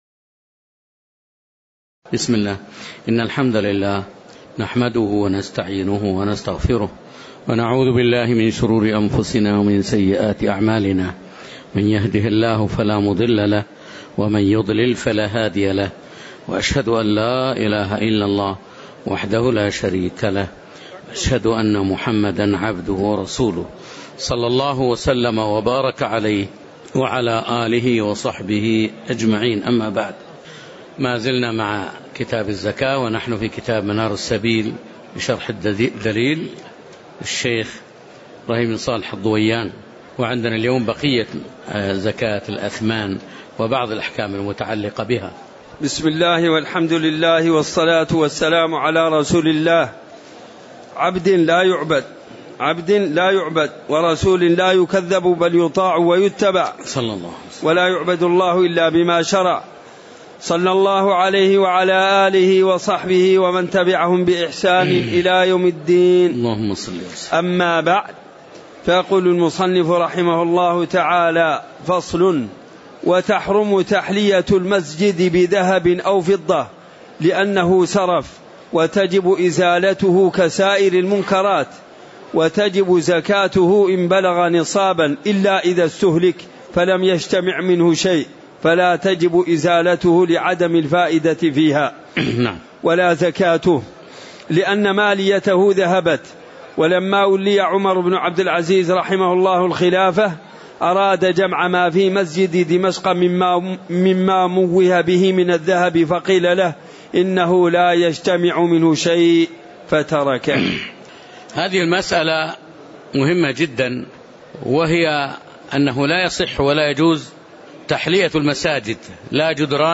تاريخ النشر ٦ جمادى الآخرة ١٤٣٩ هـ المكان: المسجد النبوي الشيخ